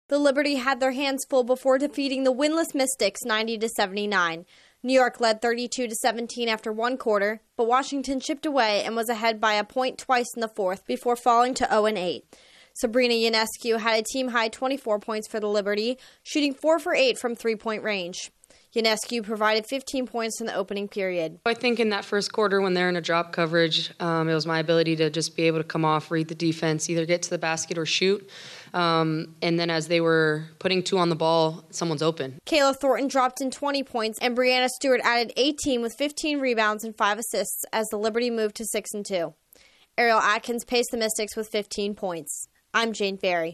The Liberty eke out another close win. Correspondent